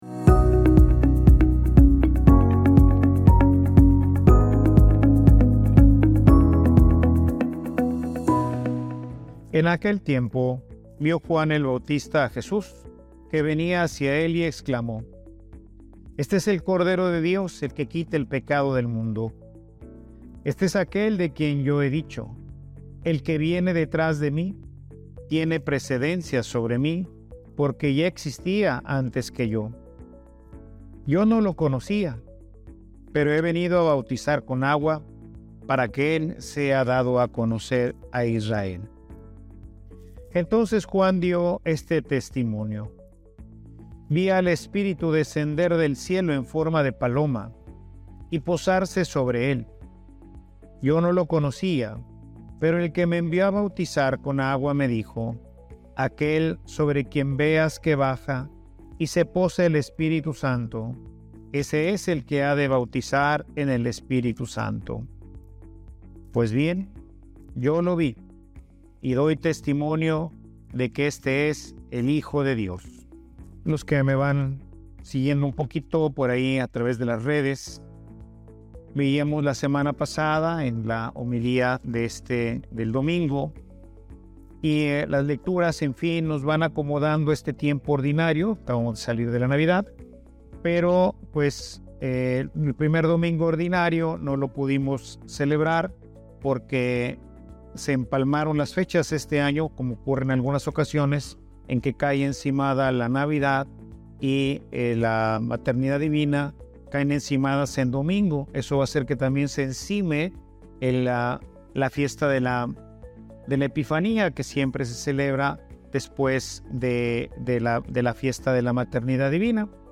Homilia_seamos_cristianos_visibles.mp3